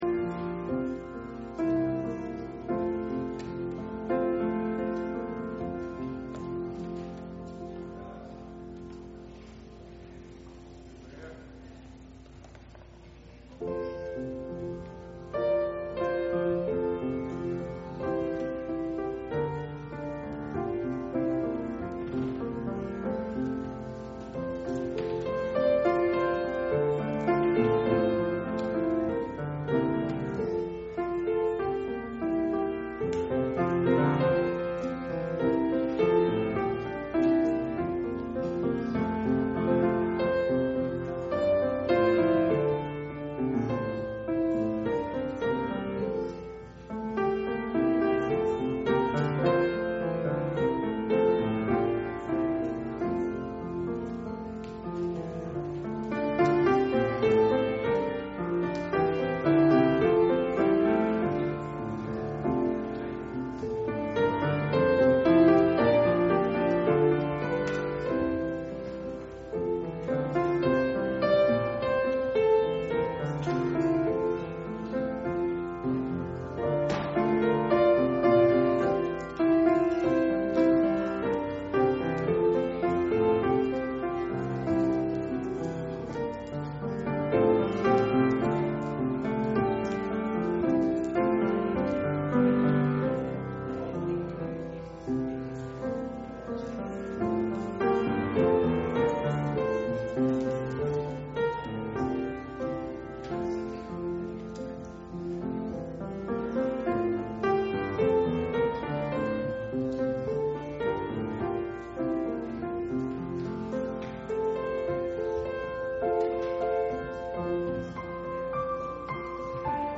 Public Reading of Holy Scripture
Service Type: Sunday Afternoon